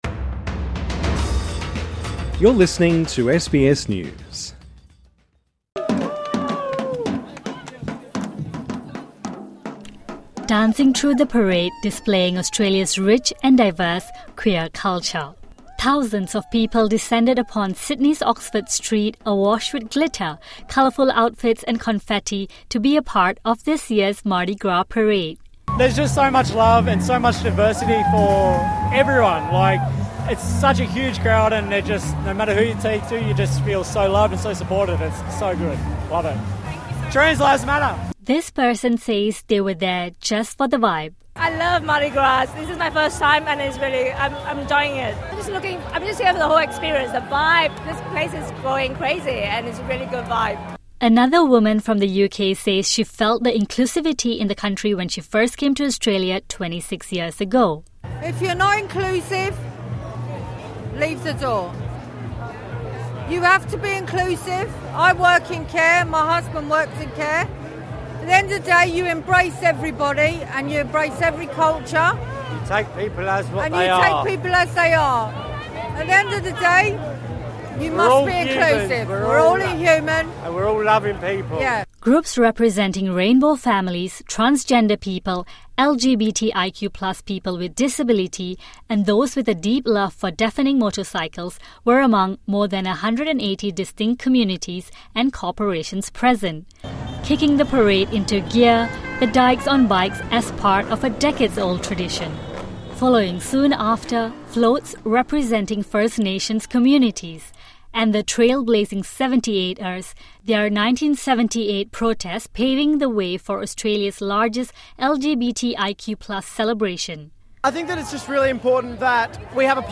People participate in the 47th annual Sydney Gay and Lesbian Mardi Gras Parade on Oxford Street, in Sydney, New South Wales.